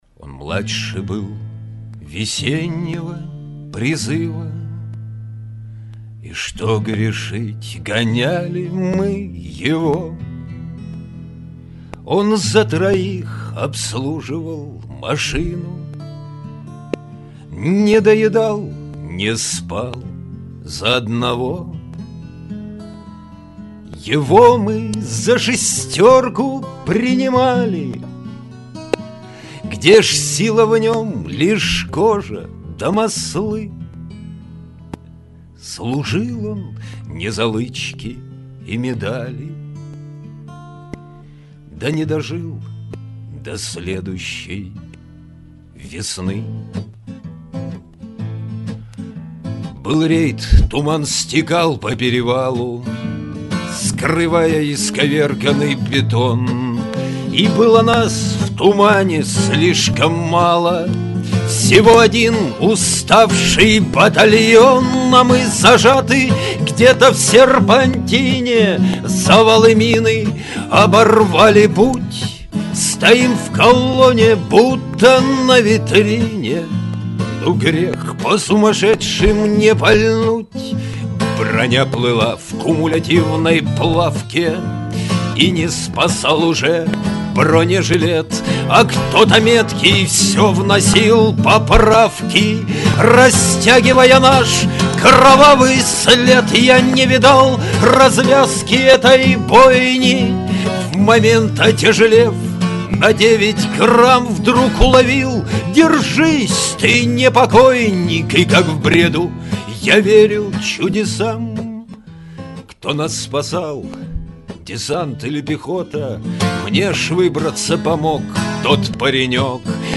Авторская песня
Играет на 6 и 12-ти струнной гитарах.